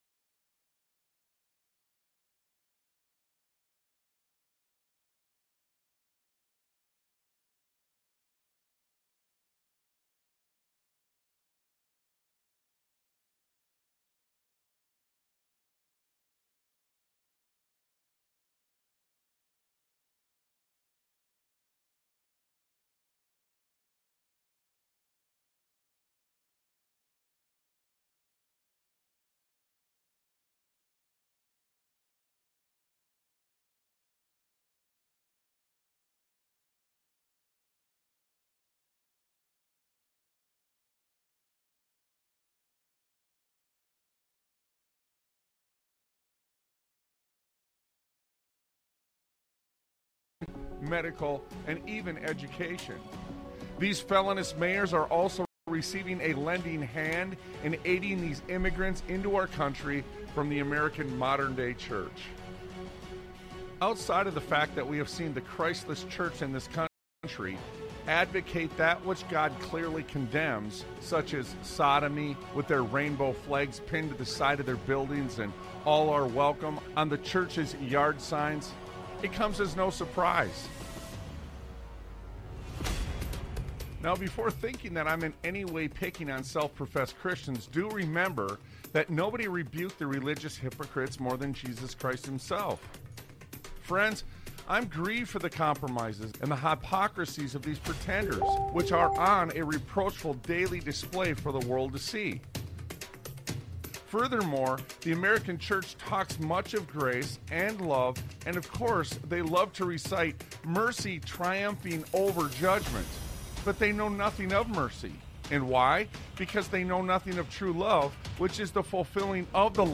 Talk Show Episode, Audio Podcast, Sons of Liberty Radio and I Am Set For The Defense on , show guests , about I Am Set For The Defense, categorized as History,News,Philosophy,Politics & Government,Christianity,Society and Culture,Technology